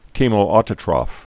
(kēmōtə-trŏf, -trōf, kĕmō-)